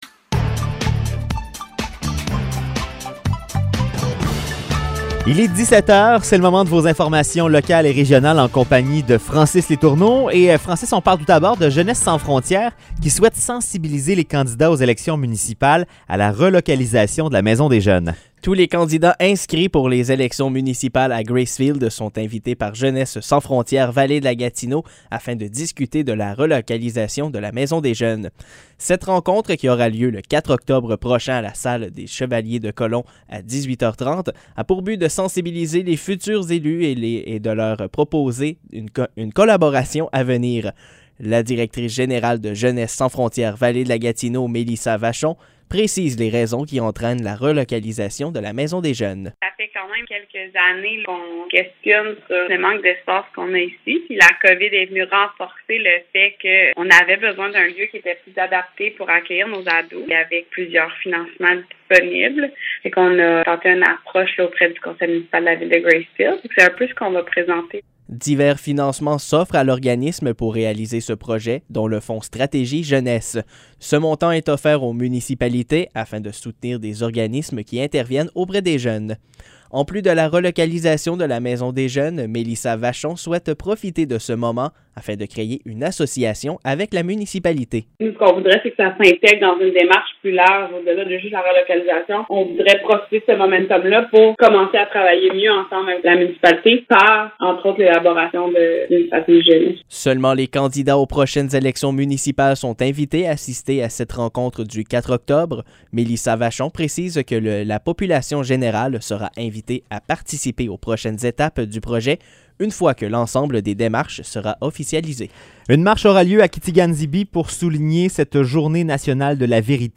Bulletins de nouvelles